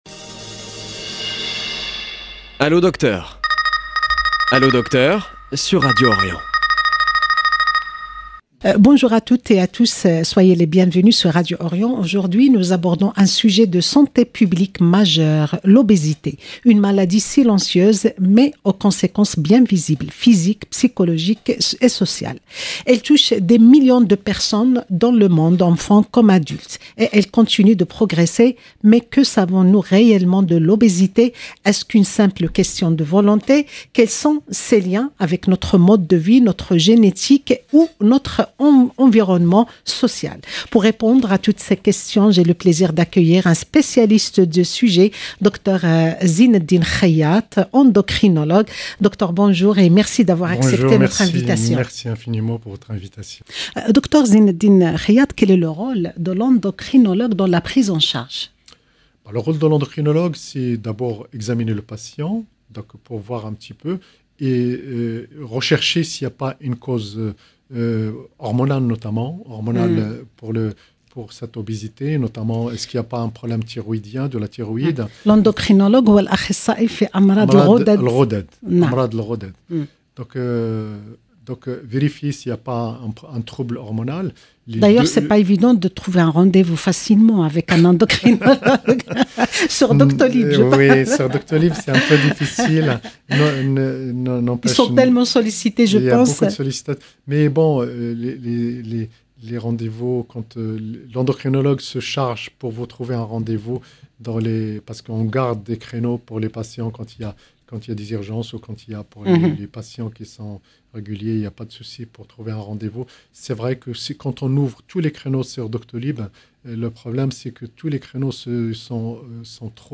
endocrinologue. 0:00 1 sec